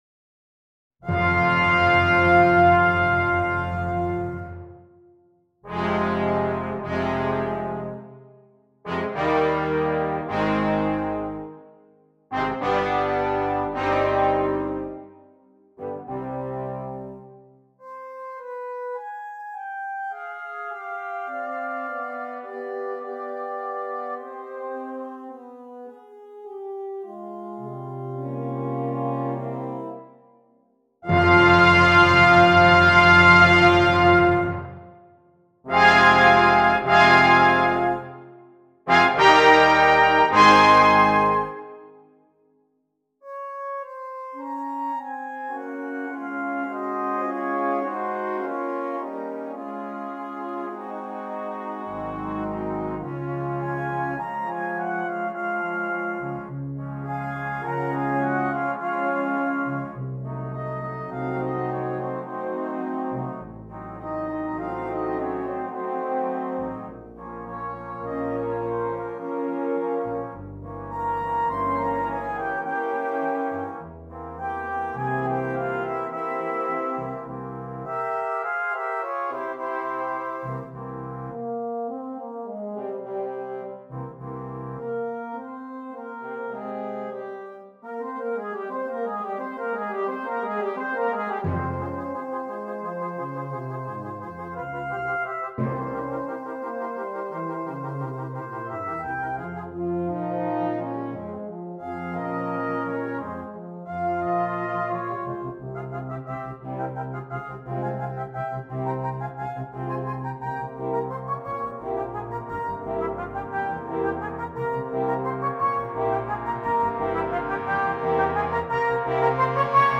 Brass Choir (4.4.3.1.1.timp)